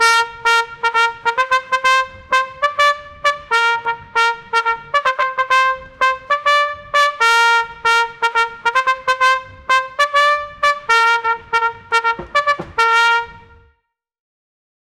dance-pop, trumpet